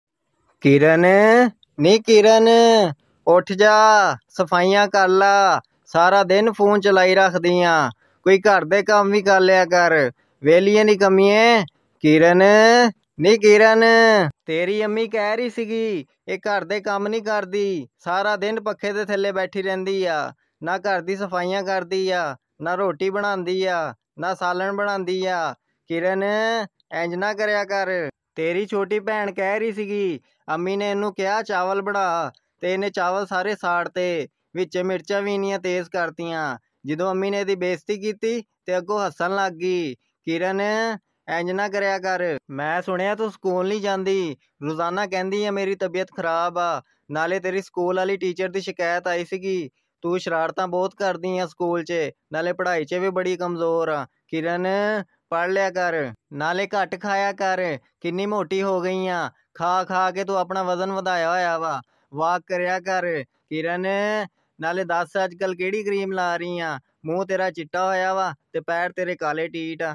Goat Calling Name Kiran Funny sound effects free download